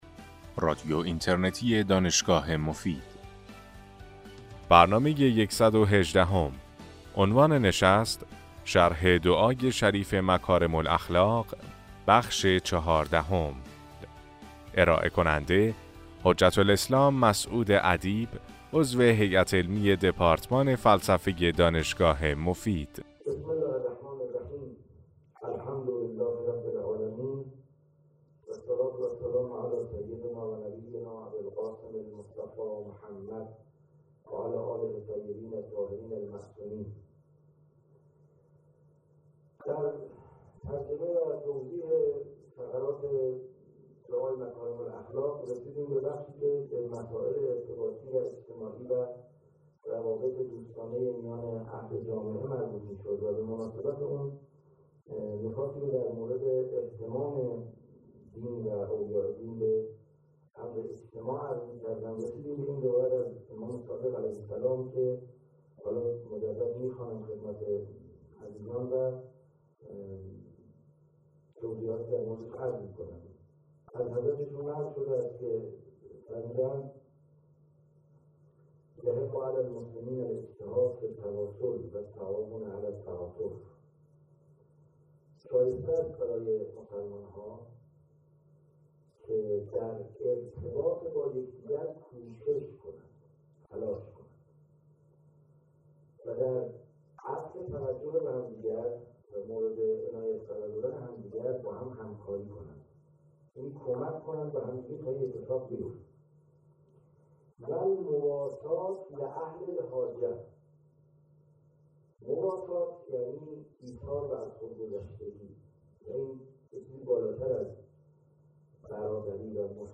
در این سلسله سخنرانی که در ماه رمضان سال 1395 ایراد شده است به شرح و تفسیر معانی بلند دعای مکارم الاخلاق (دعای بیستم صحیفه سجادیه) می پردازند.